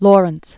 (lôrənts), Hendrik Antoon 1853-1928.